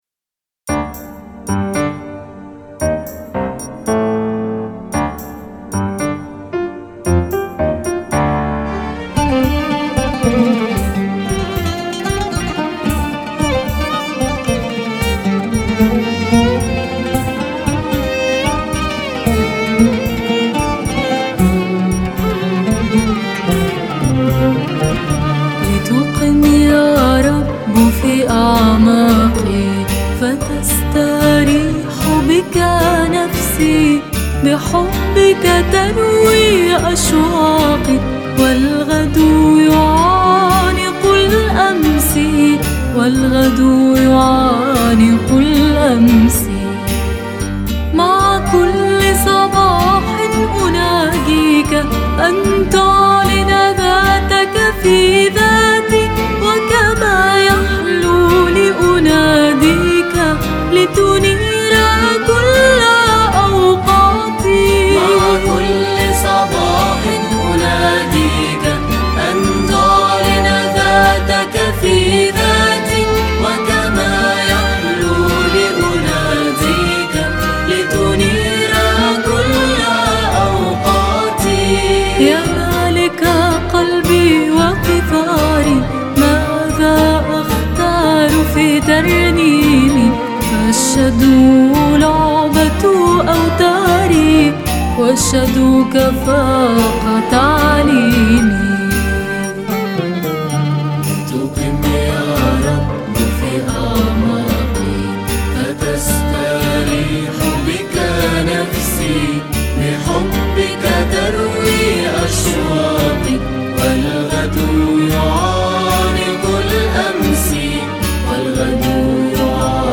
كورال
كمان
عود
جيتار